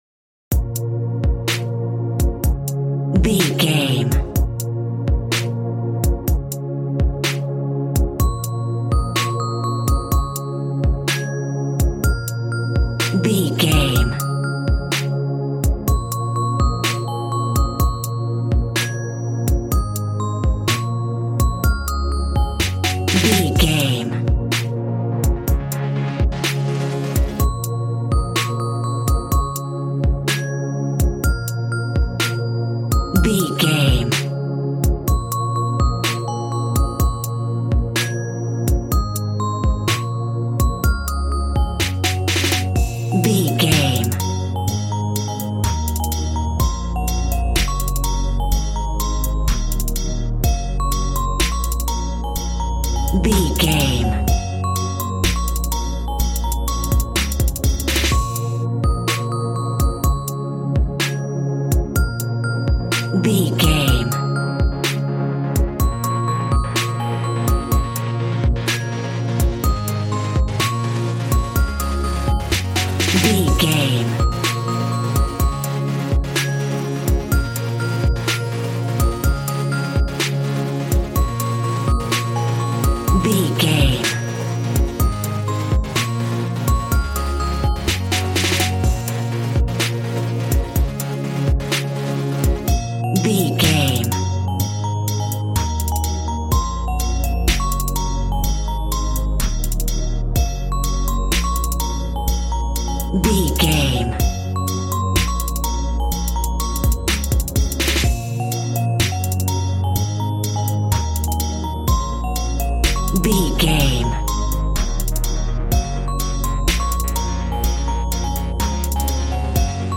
Aeolian/Minor
B♭
calm
smooth
synthesiser
piano